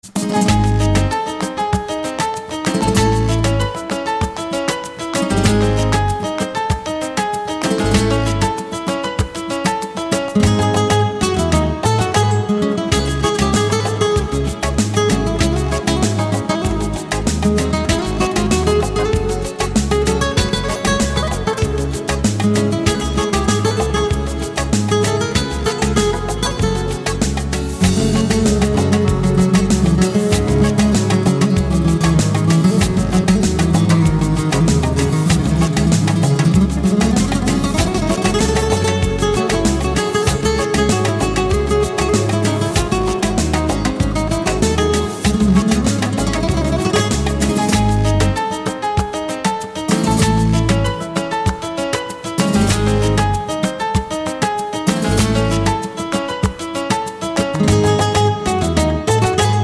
• Качество: 192, Stereo
гитара
красивые
без слов
инструментальные
испанская гитара